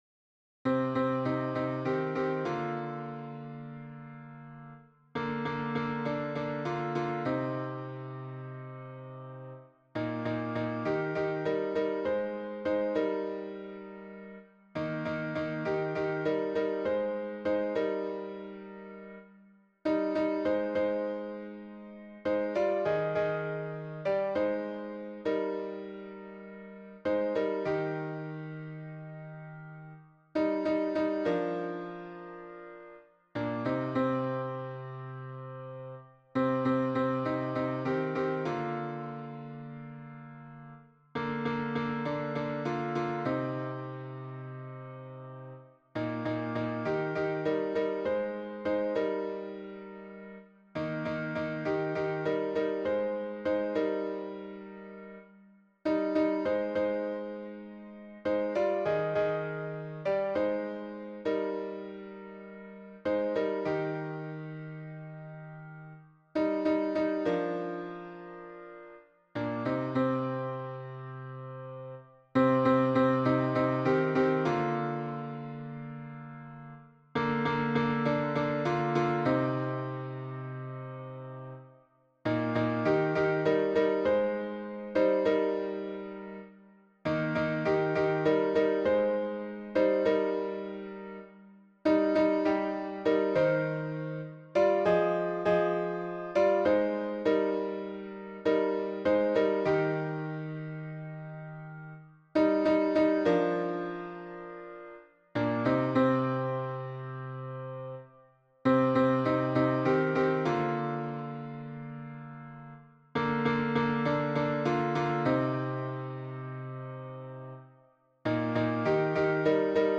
MP3 version piano - 3 voix (pour la chorale)